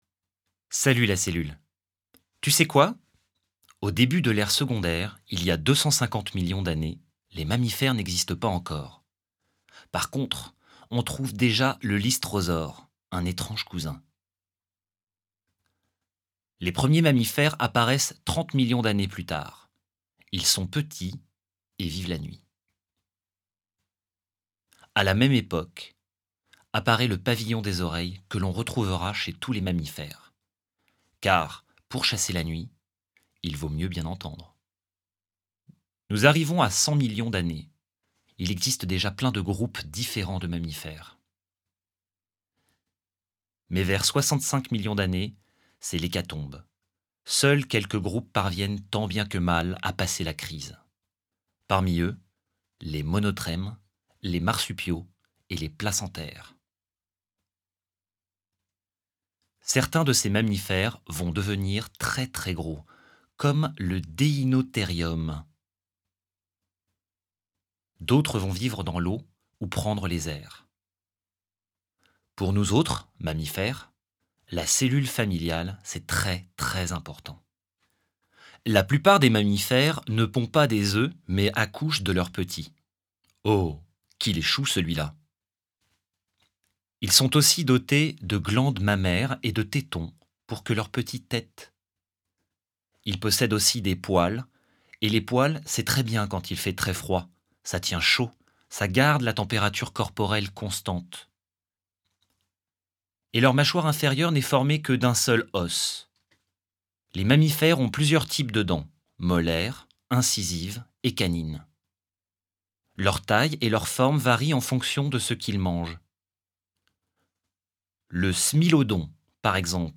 voix pour un parcours musée + didactique